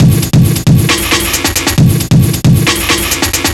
Bad Beat 135.wav